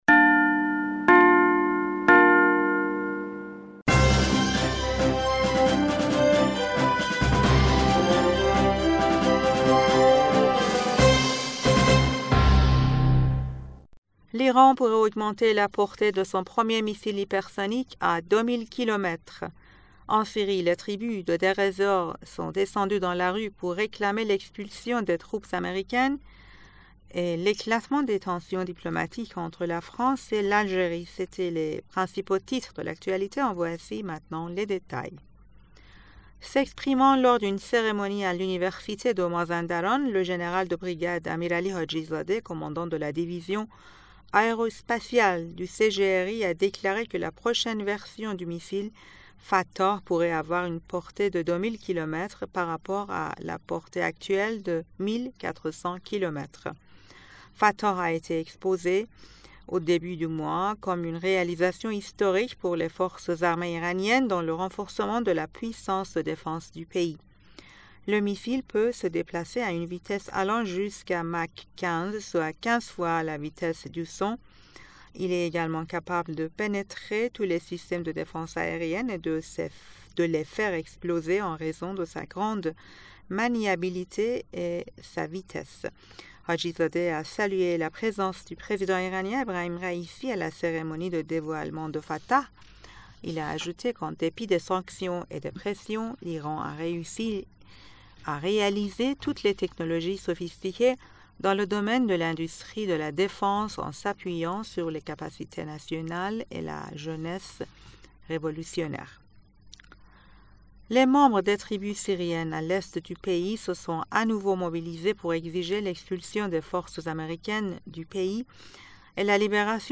Bulletin d'information du 23 Juin 2023